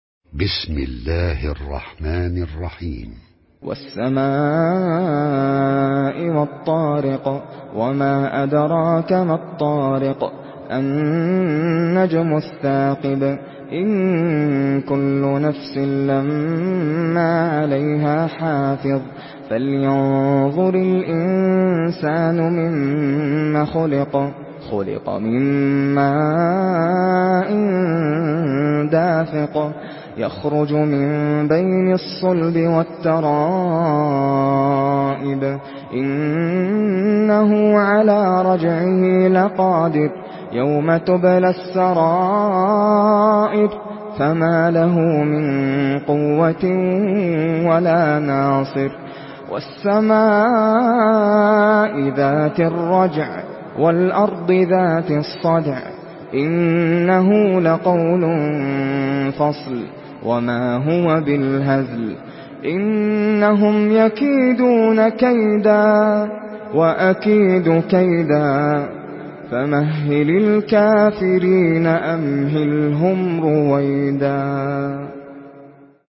Surah আত-ত্বারেক MP3 by Nasser Al Qatami in Hafs An Asim narration.
Murattal Hafs An Asim